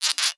ihob/Assets/Extensions/CartoonGamesSoundEffects/Scratch_v1/Scratch_v1_wav.wav
Scratch_v1_wav.wav